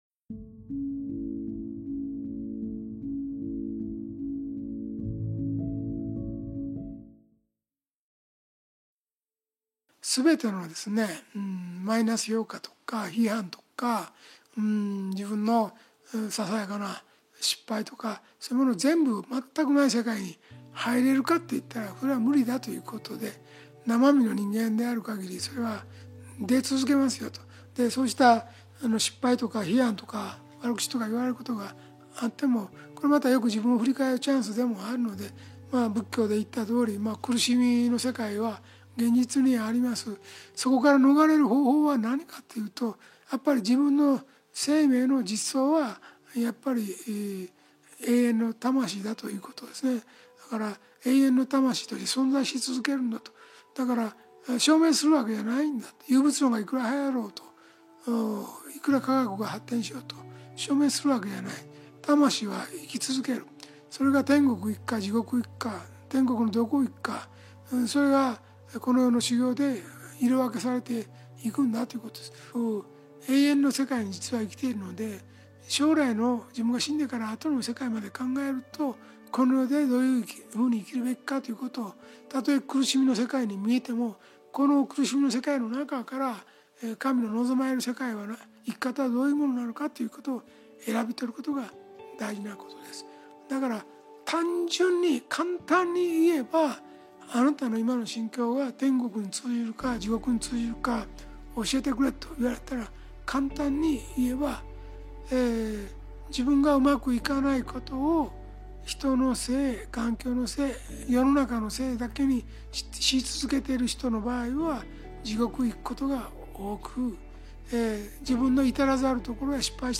ラジオ番組「天使のモーニングコール」で過去に放送された、幸福の科学 大川隆法総裁の説法集です。
大川隆法総裁「苦しみの世界」より